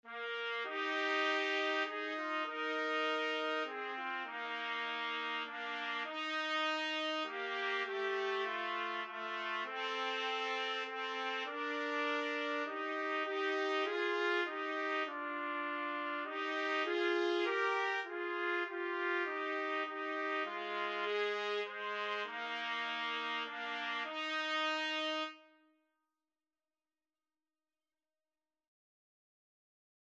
3/4 (View more 3/4 Music)
Trumpet Duet  (View more Easy Trumpet Duet Music)
Classical (View more Classical Trumpet Duet Music)